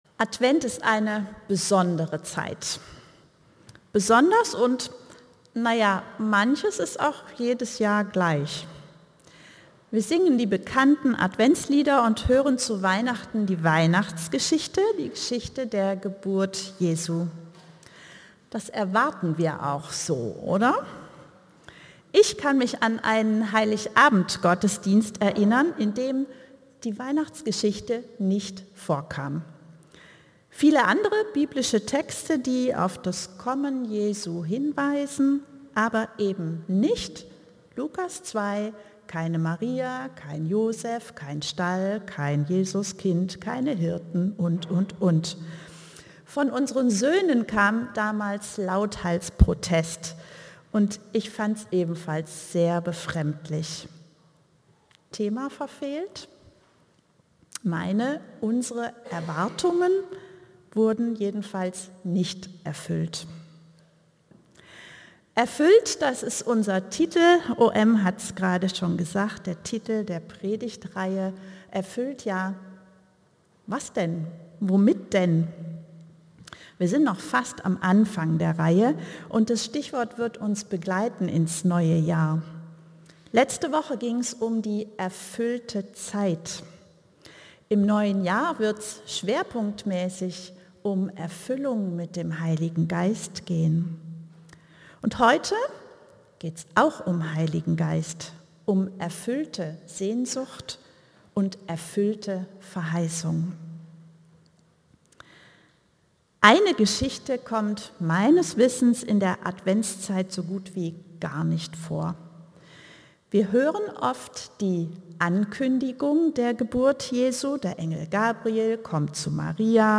Predigten aus einANDERERGottesdienst